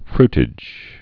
(frtĭj)